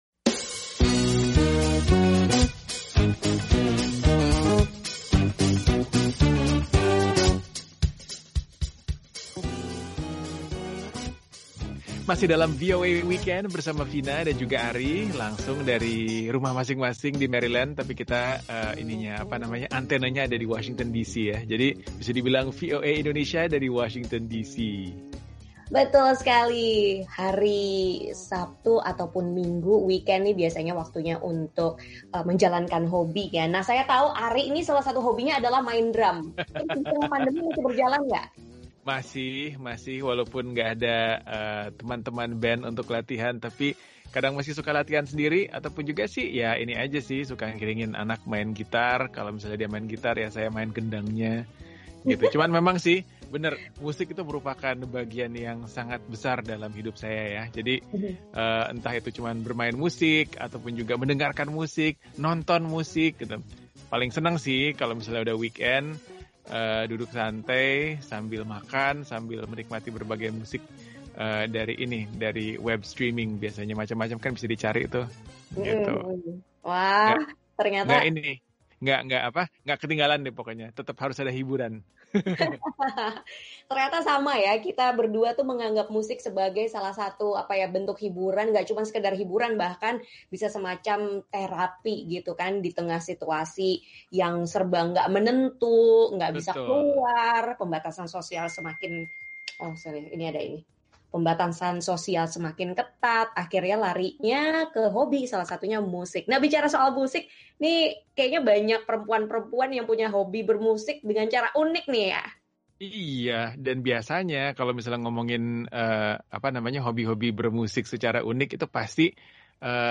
Satu kelompok perempuan yang membuat musik dengan peralatan rumah tangga, berupaya memberdayakan perempuan lain di Republik Demokratik Kongo. “Les Mamans du Congo” menggunakan alu dan lesung raksasa untuk mengiringi suara drum, derap kaki dan lirik-lirik yang memberi semangat